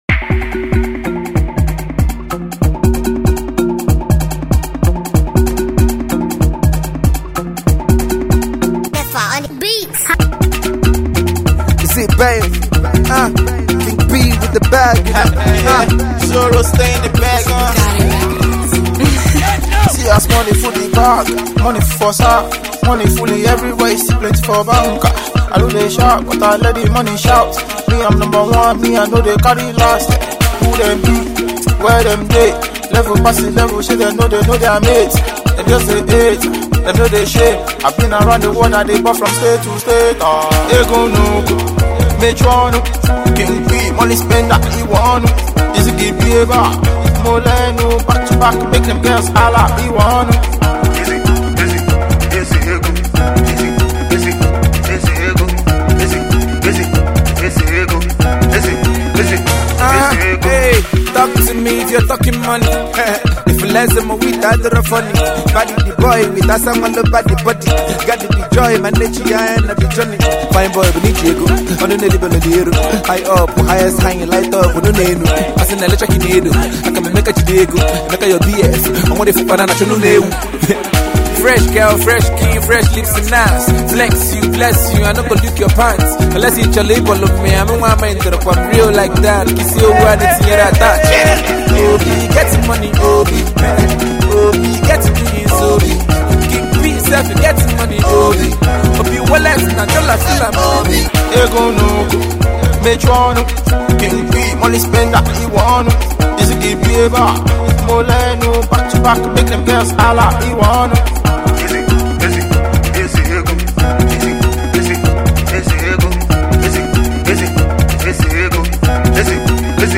prolific igbo rapper